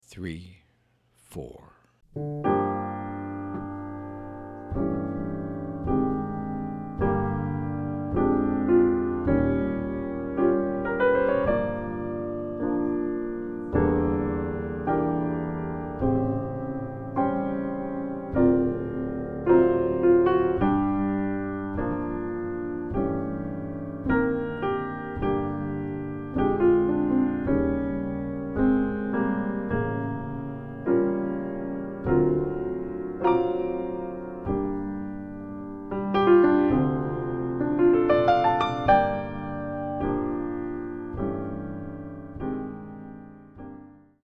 An impassioned ballad
piano